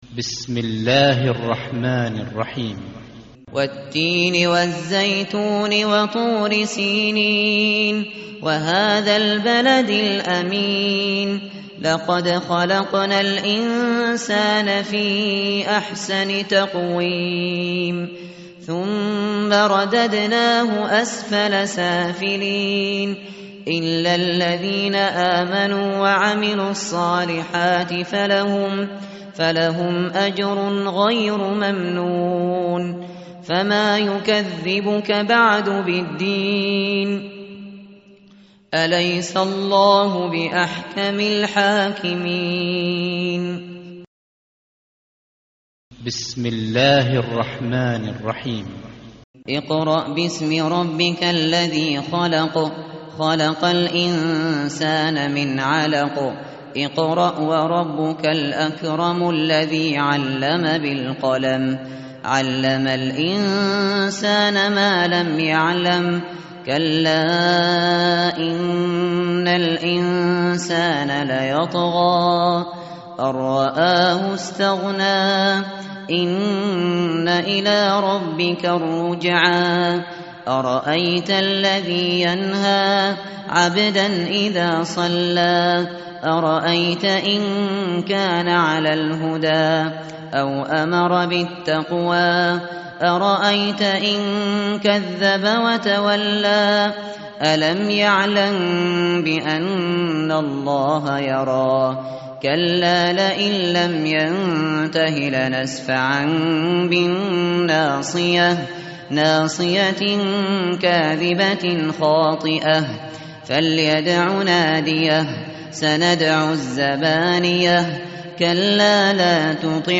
tartil_shateri_page_597.mp3